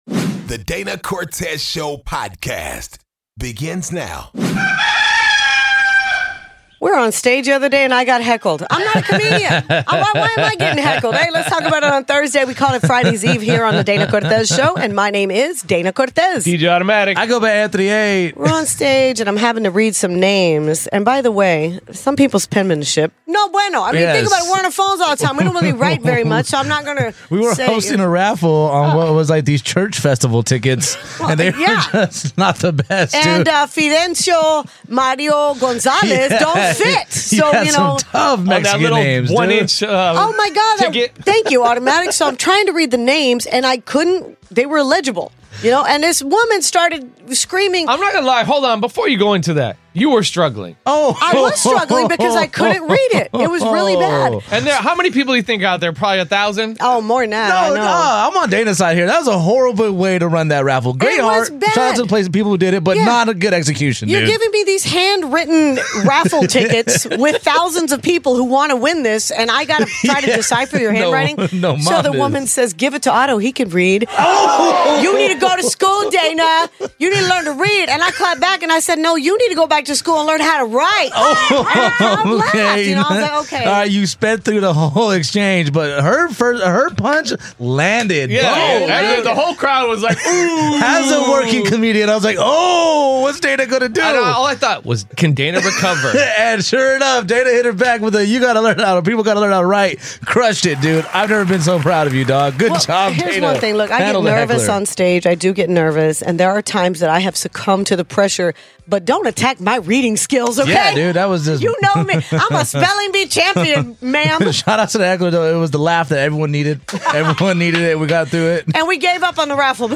A listener called in and said his wife doesn't think he's pulling his weight with the baby and he says he shouldn't have to because he has a full time job. Who's in the right?